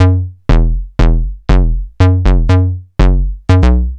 TSNRG2 Bassline 007.wav